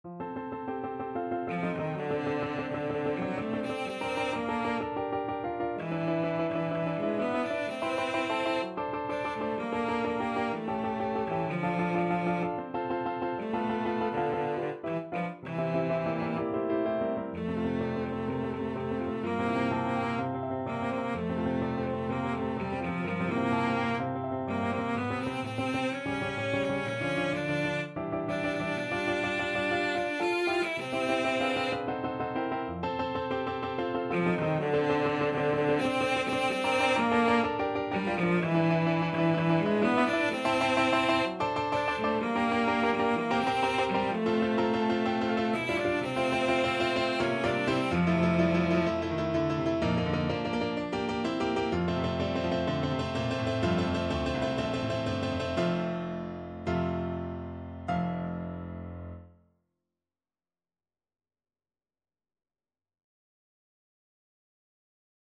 Cello version